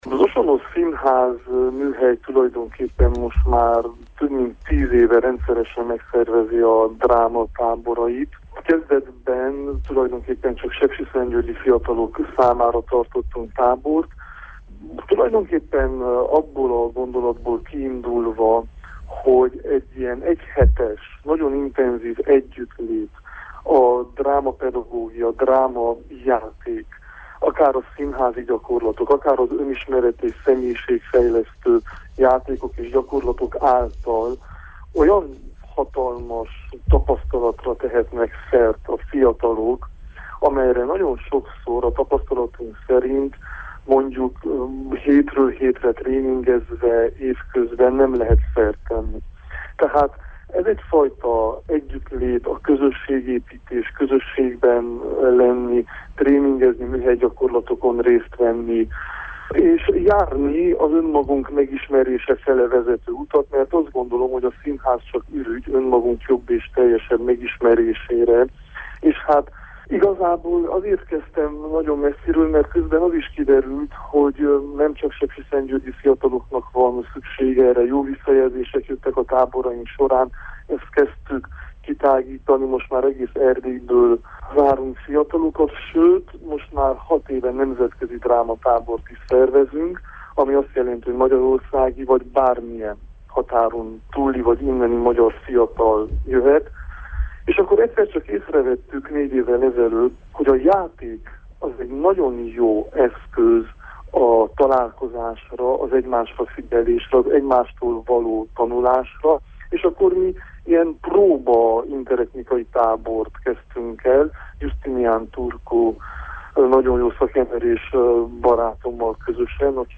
színész-pedagógus a KULTÚRPRESSZÓ vendége